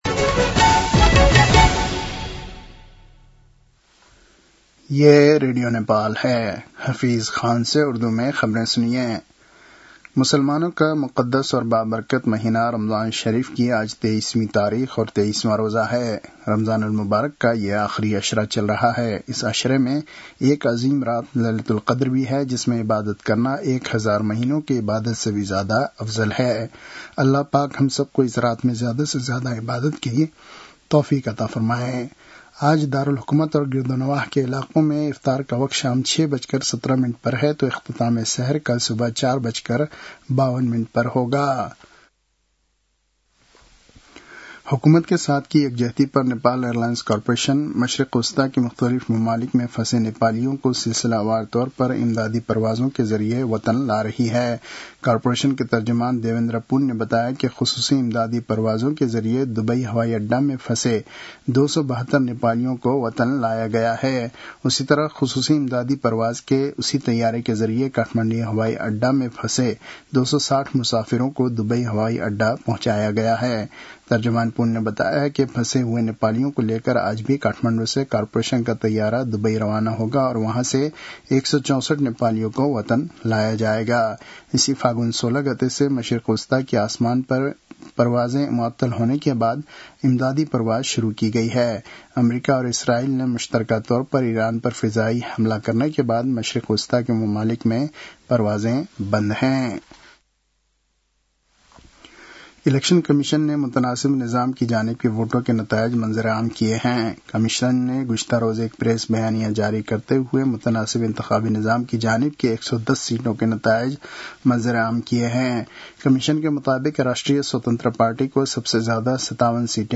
उर्दु भाषामा समाचार : २९ फागुन , २०८२
Urdu-news-11-29.mp3